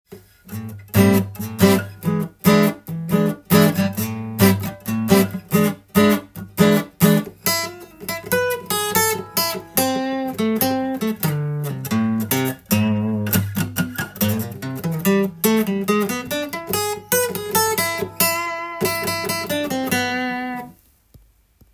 カンタンおしゃれコード
Am/E7
全ての音源で適当に弾いているだけですが
②は、暗くておしゃれな曲で使われるコード進行です。